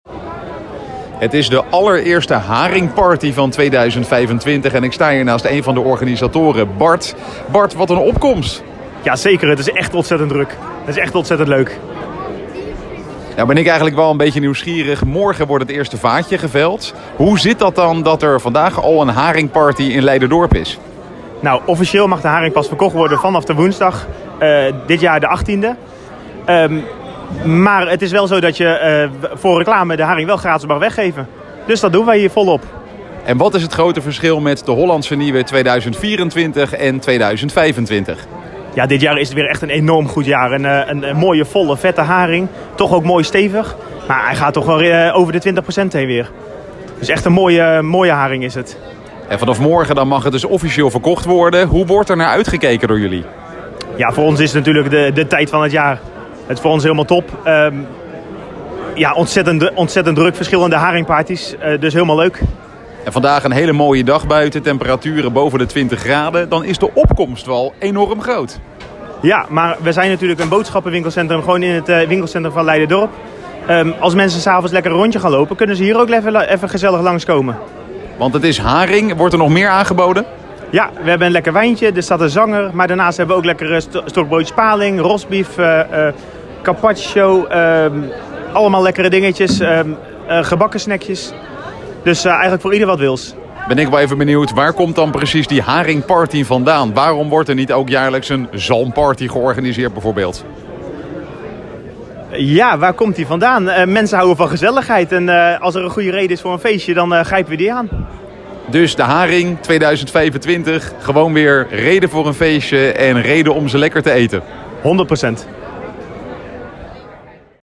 In Winkelcentrum Winkelhof in Leiderdorp is dinsdagavond feestelijk gevierd dat er weer ‘Hollandse Nieuwe’ is.